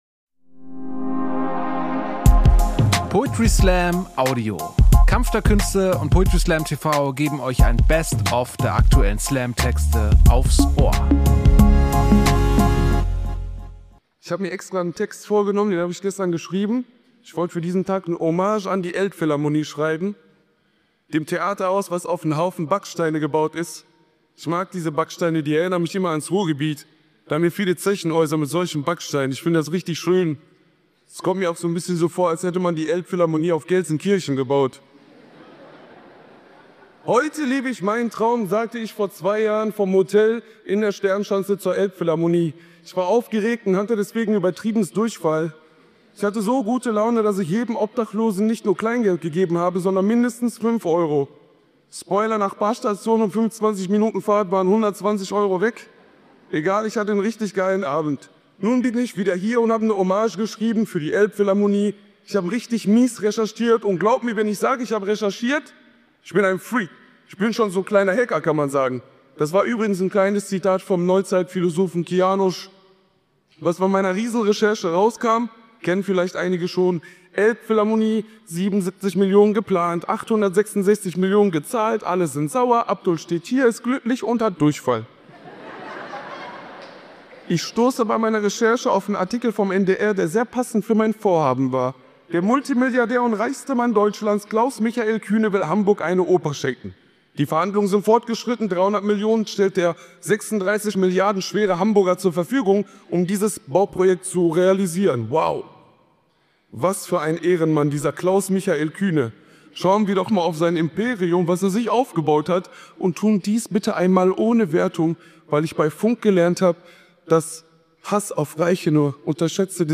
Kunst , Comedy , Gesellschaft & Kultur
Stage: Elbphilharmonie, Hamburg